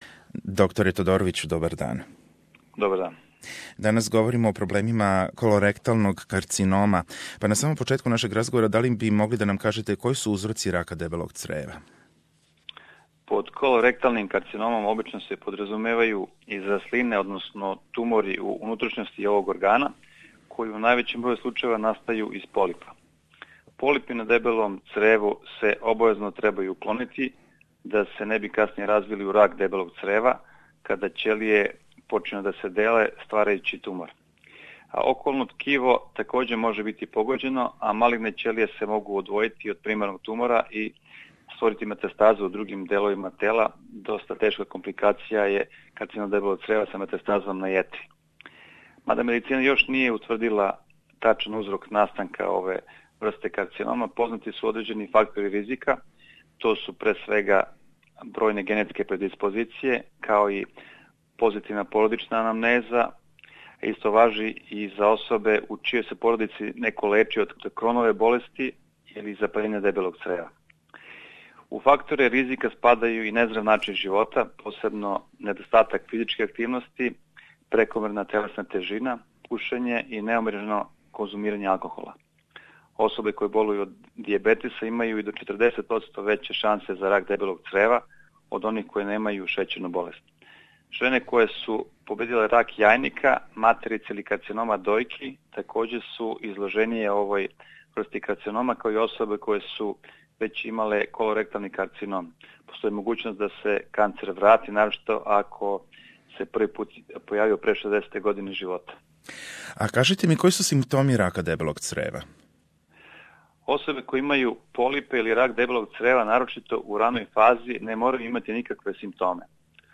Рак дебелог црева, стручно назван колоректални карцином, једно је од најучесталијих малигних обољења. У разговору